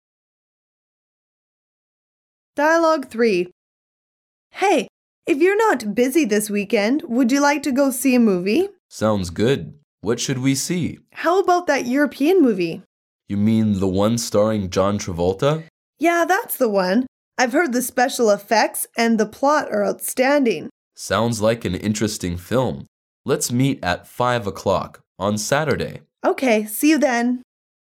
Dialoug 3